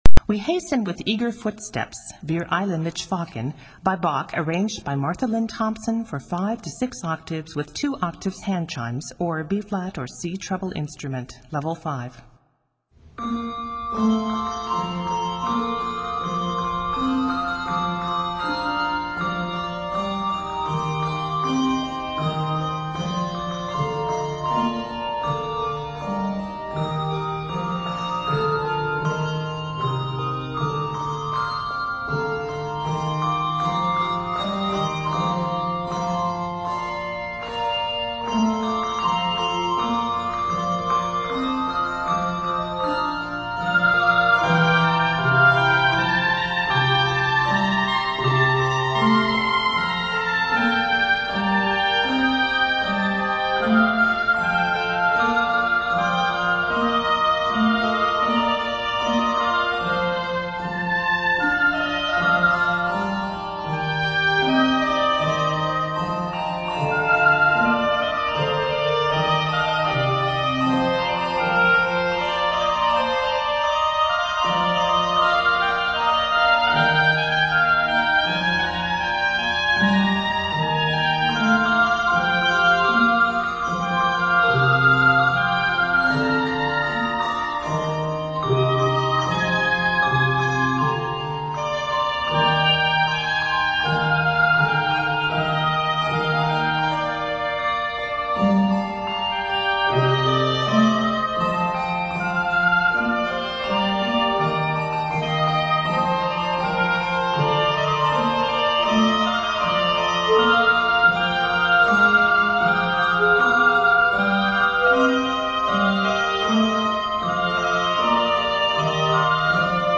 bell and chime